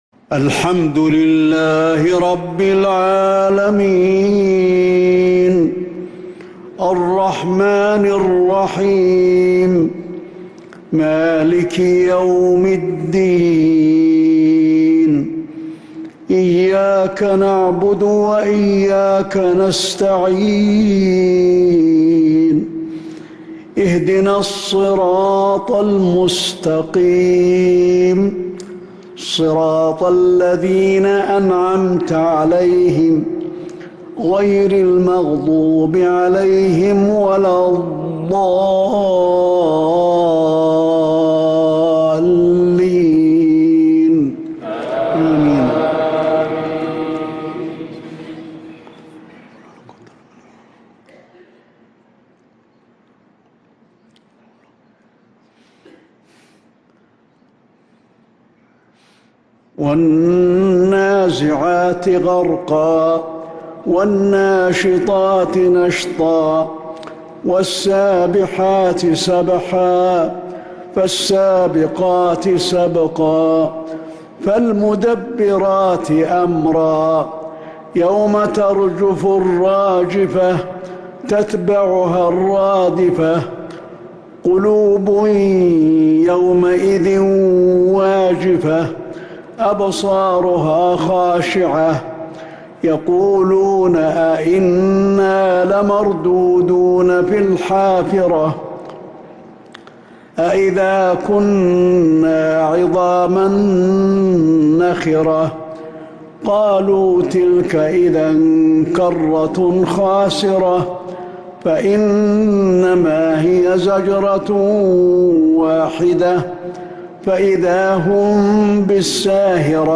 صلاة العشاء للقارئ علي الحذيفي 10 ذو القعدة 1445 هـ
تِلَاوَات الْحَرَمَيْن .